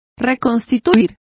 Complete with pronunciation of the translation of reconstructing.